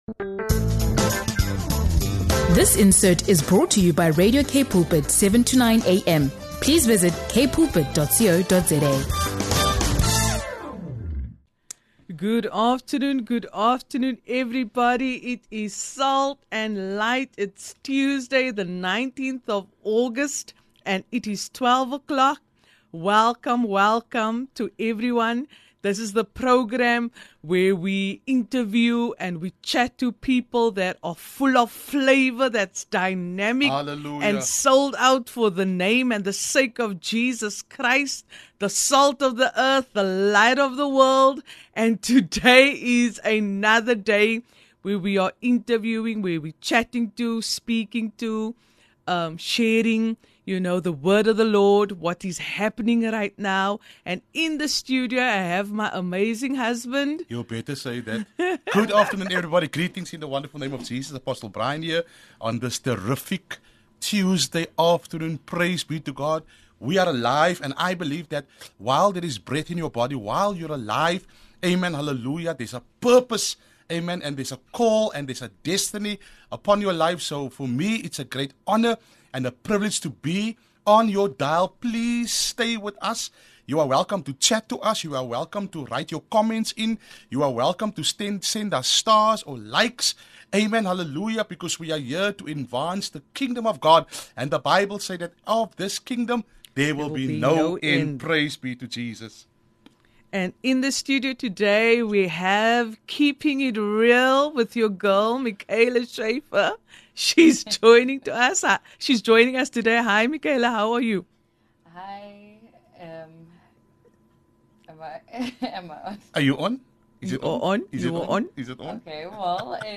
Tune in for an inspiring conversation about leadership, faith, and making a difference in the community.